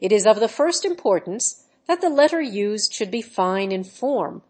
Add TTS with the Models XTTS pre trained with modi voice